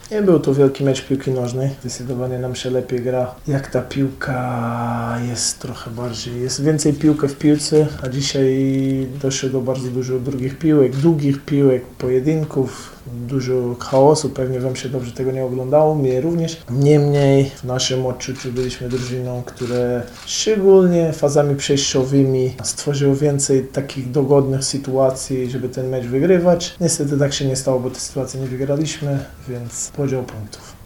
– Nie był to wielki mecz piłki nożnej – przyznał na konferencji pomeczowej Gonçalo Feio, szkoleniowiec Legii Warszawa.